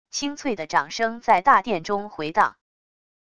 清脆的掌声在大殿中回荡wav音频